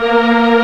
Index of /90_sSampleCDs/Giga Samples Collection/Organ/MightyWurltzBras